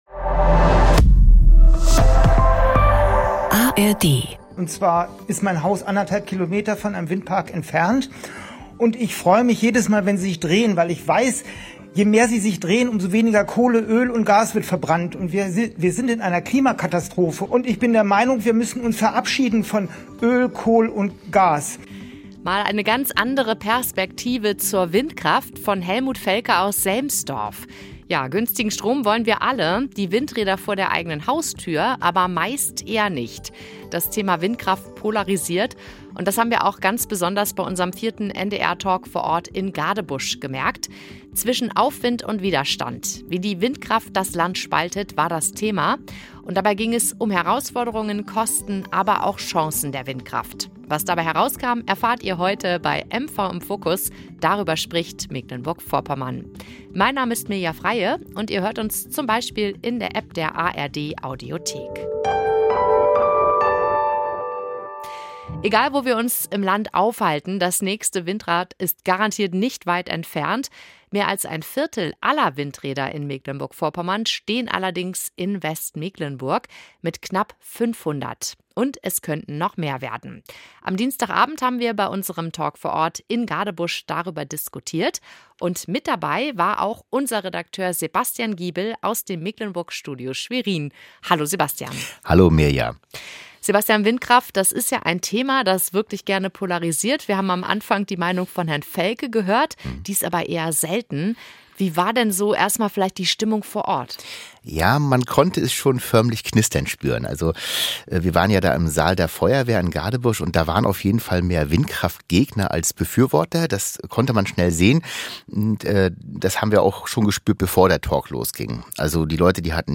Beim NRD MV Talk vor Ort in Gadebusch ging es um die Windenergie: Planung, Beteiligung und Ausblick in die Zukunft.